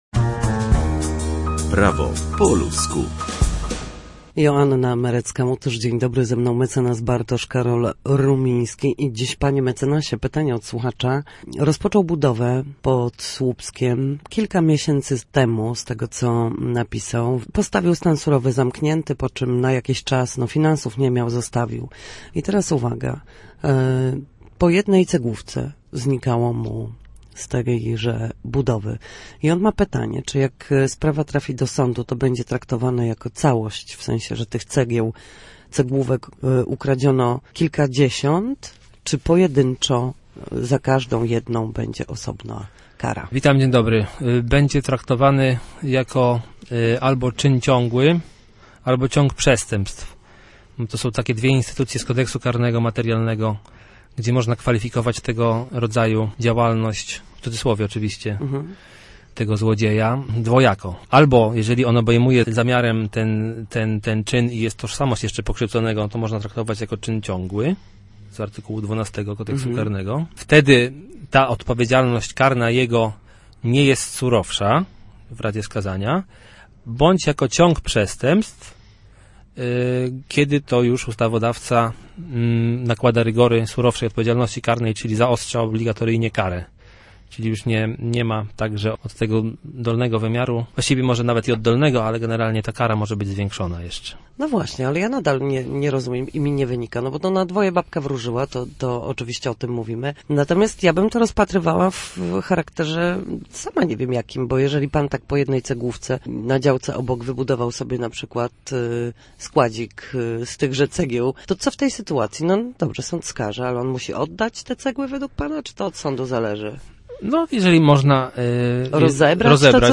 W każdy wtorek o godzinie 13:40 na antenie Studia Słupsk przybliżamy Państwu meandry prawa.
Nasi goście, prawnicy, odpowiadają na jedno pytanie dotyczące zachowania w sądzie lub podstawowych zagadnień prawniczych.